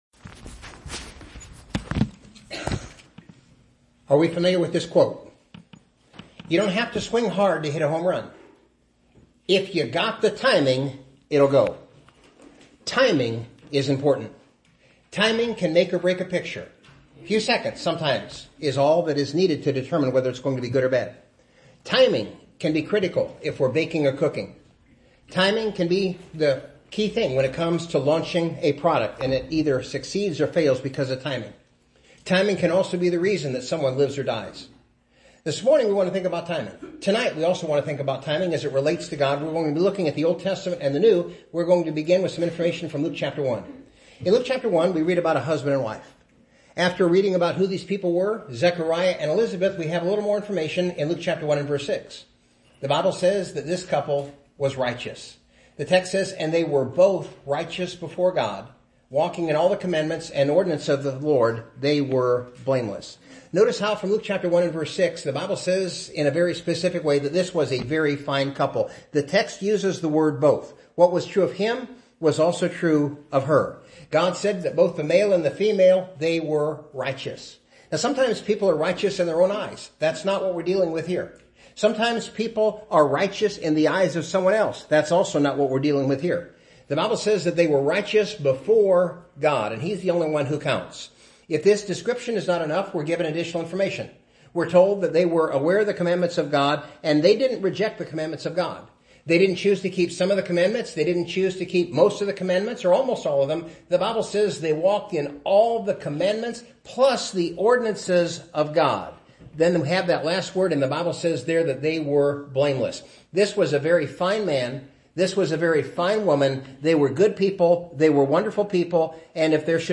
sermon-on-gods-timing.mp3